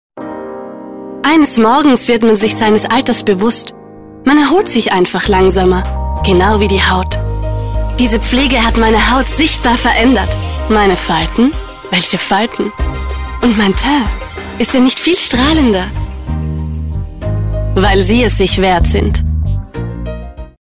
Erklärfilm SEO kompetent